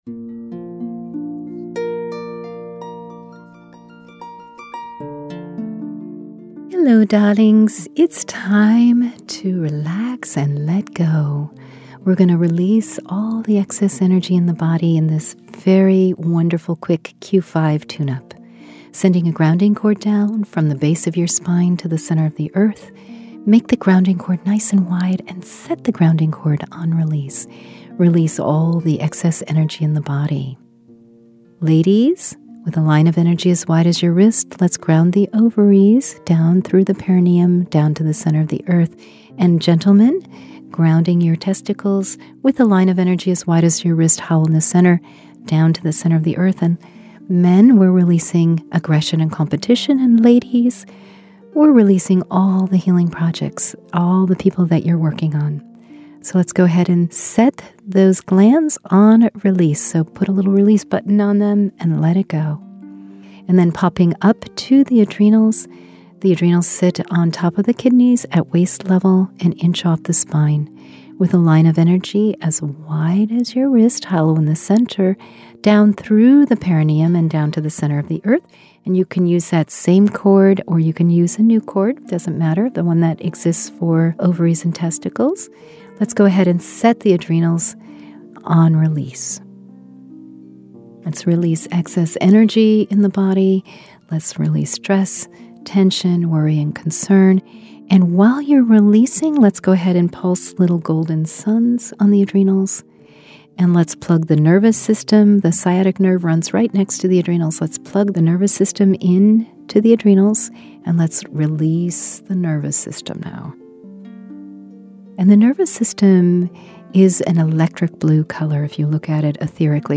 Meditations